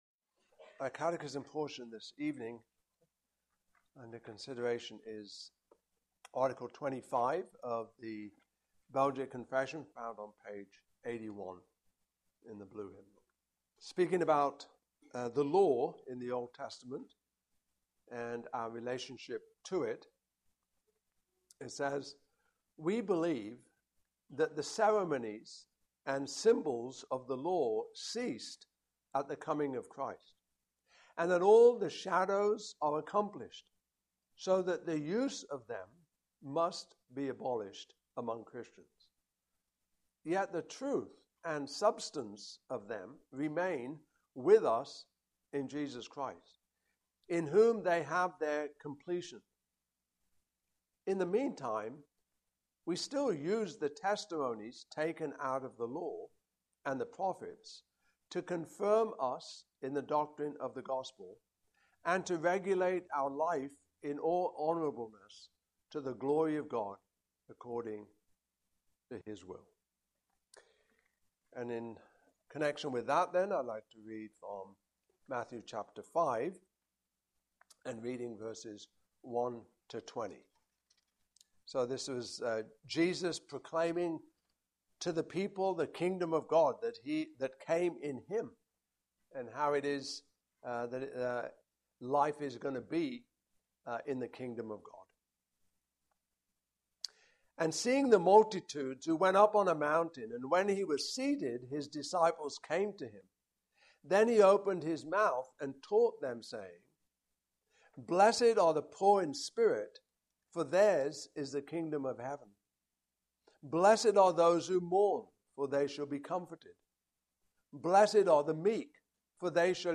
Passage: Matthew 5:1-20 Service Type: Evening Service